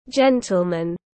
Quý ông tiếng anh gọi là gentleman, phiên âm tiếng anh đọc là /ˈdʒen.təl.mən/.
Gentleman /ˈdʒen.təl.mən/